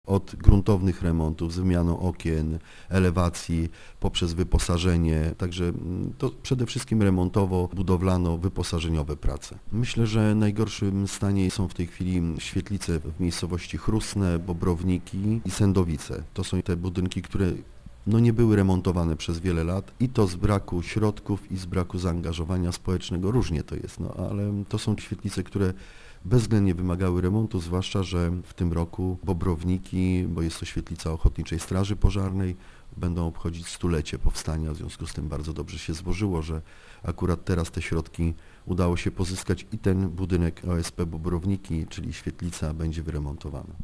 - Wszystkie obiekty są w kiepskim stanie, a w niektórych przypadkach modernizacja jest wręcz niezbędna. Inwestycje będą współfinansowane ze środków Unii Europejskiej, a zakres robót w poszczególnych miejscowościach będzie różny - mówi Informacyjnej Agencji Samorządowej burmistrz Ryk Jerzy Gąska: